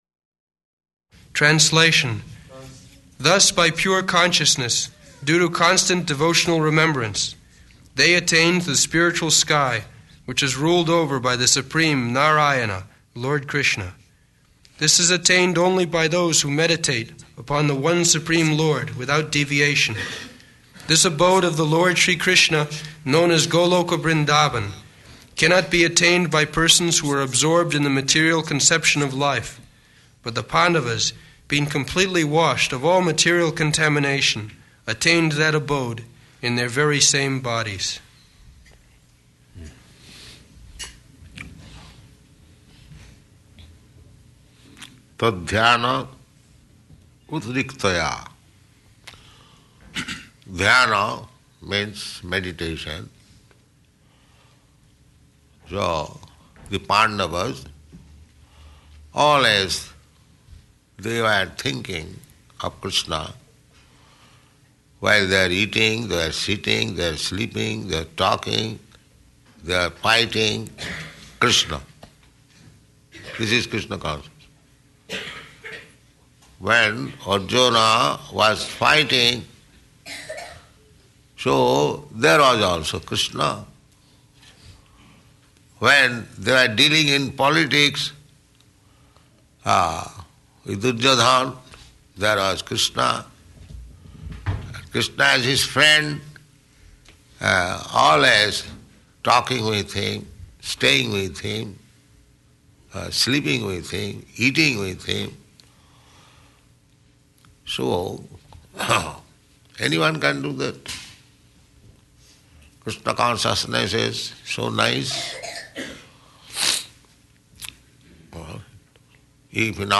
Location: Los Angeles